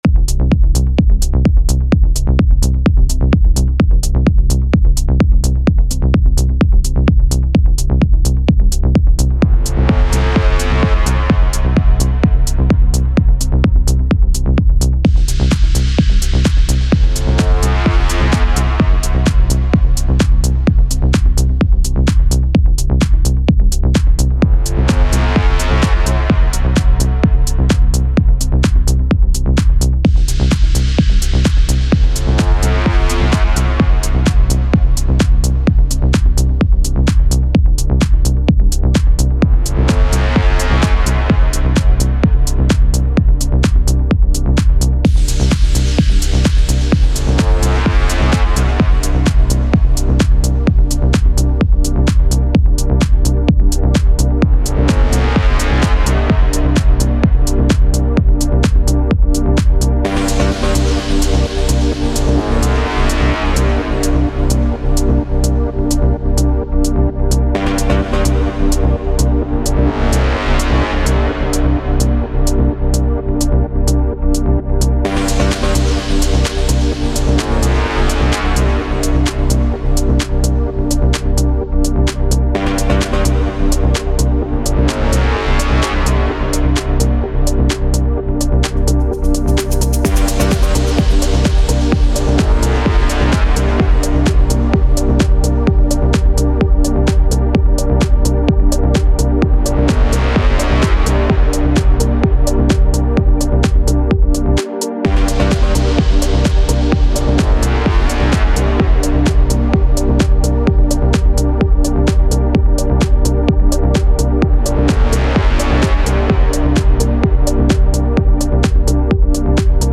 🎵PROGRESSIVE & MELODIC TECHNO, TECH HOUSE & TECHNO 🎵